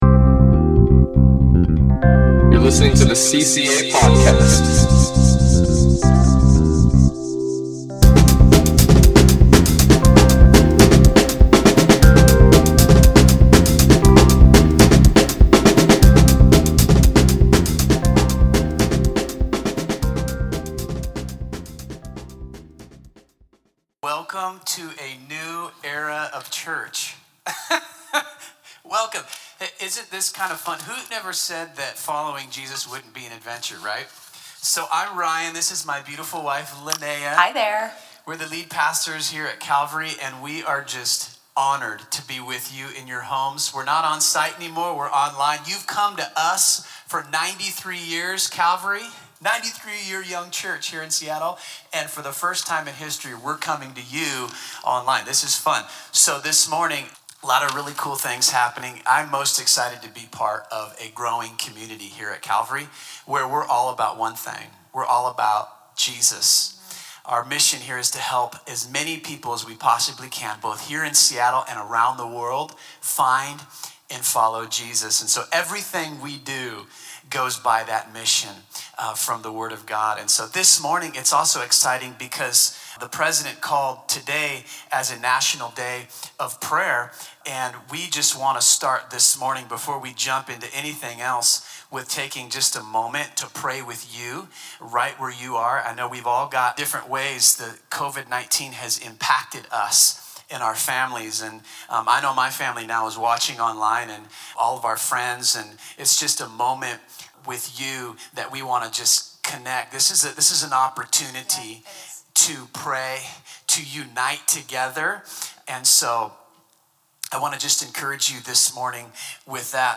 Message - Calvary Christian Assembly